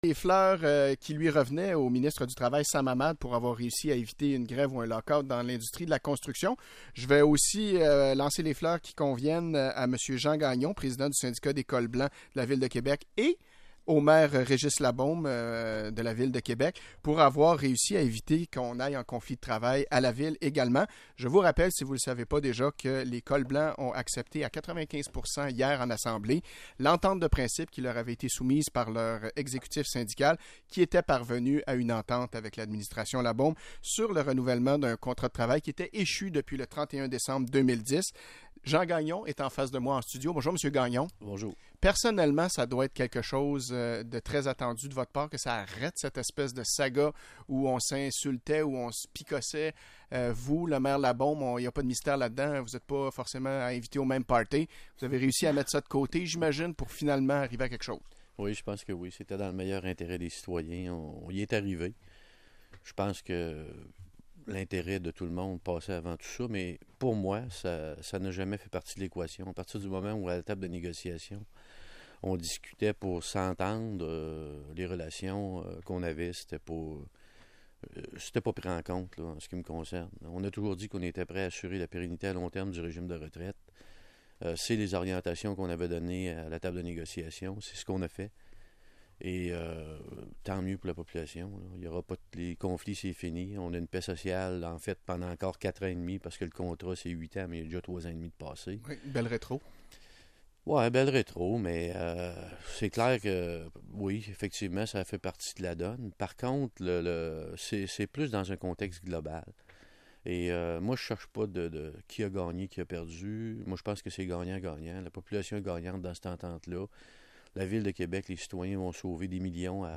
Entrevues Syndicat des fonctionnaires municipaux de Québec